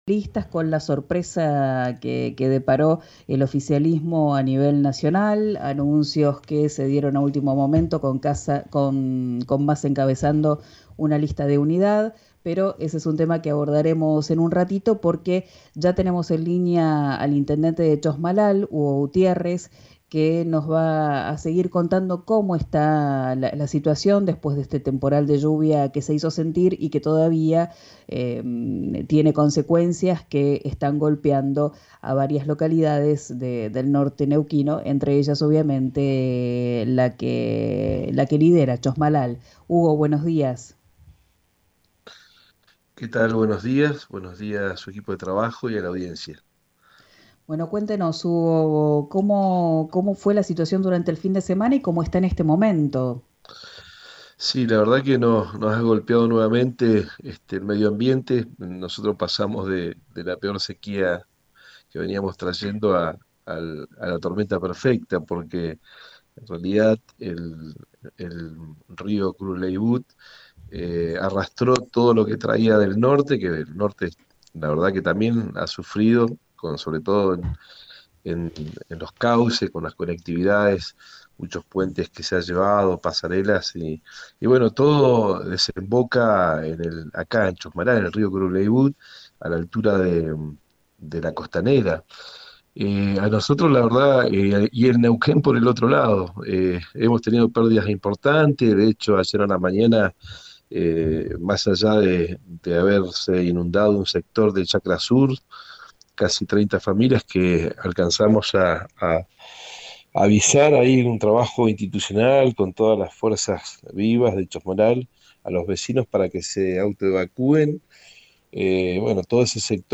El intendente Hugo Gutiérrez habló con RÍO NEGRO RADIO sobre los inconvenientes que generó la tormenta. Sostuvo que se requerirán obras para que no vuelva a pasar.